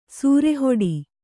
♪ sūre hoḍi